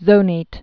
(zōnāt) also zo·nat·ed (-nātĭd)